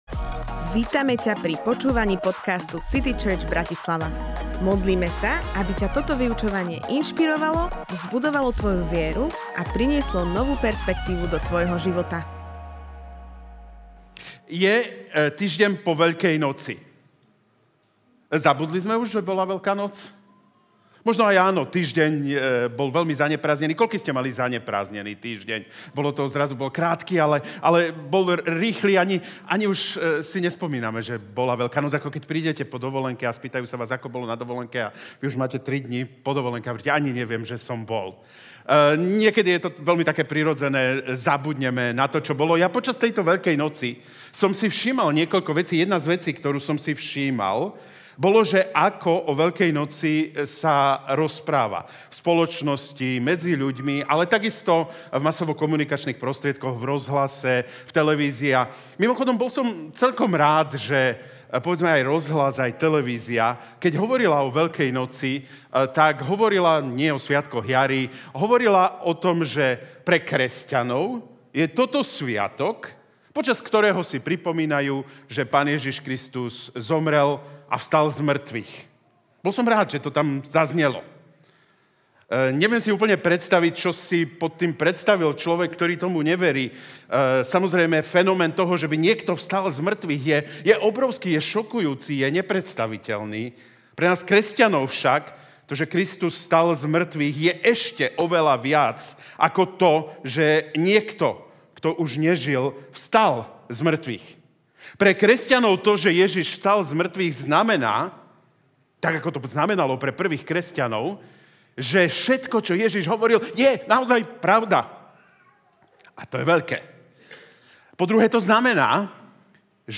Táto kázeň z príbehu v Jn 21 ukazuje, že Ježiš je bližšie, než si myslíme, aj keď Ho hneď nespoznávame.